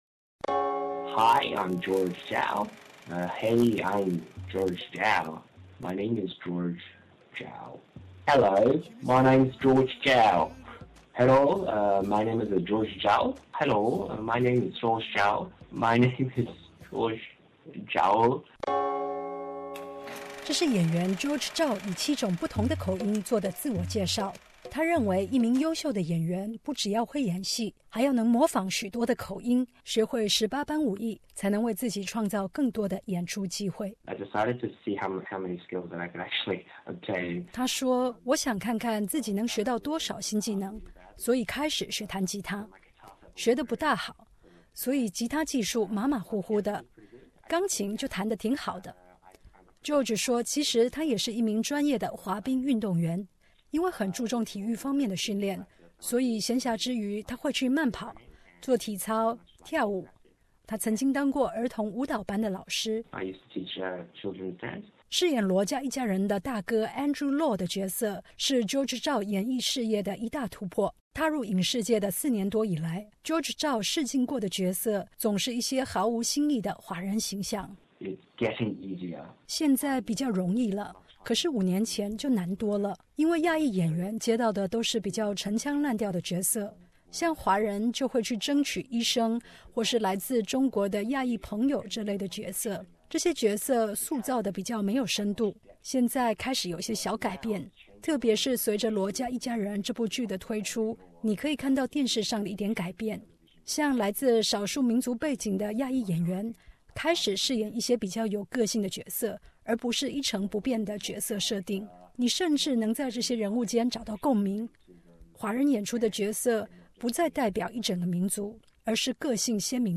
澳洲演艺圈的华裔面孔——采访《罗家趣事》中的新秀演员